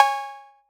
REDD PERC (1).wav